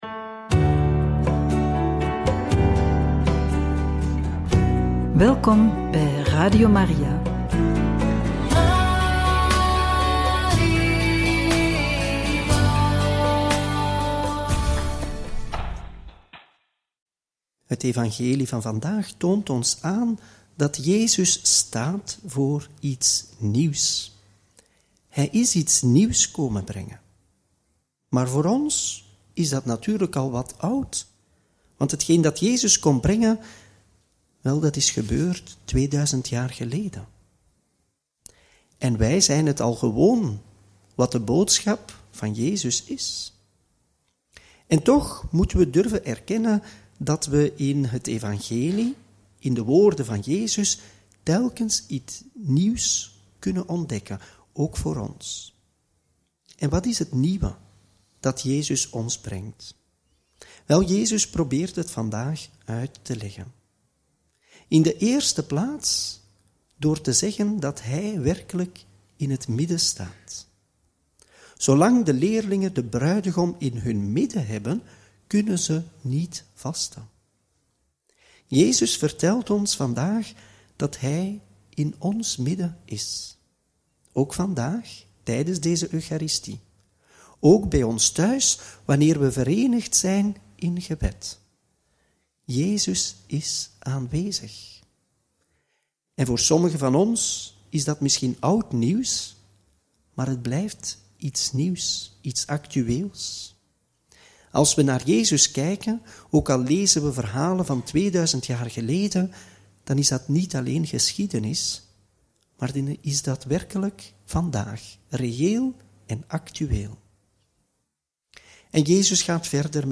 Homilie bij het Evangelie van maandag 20 januari 2025 – Marcus 2, 18-22